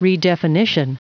Prononciation du mot redefinition en anglais (fichier audio)
Prononciation du mot : redefinition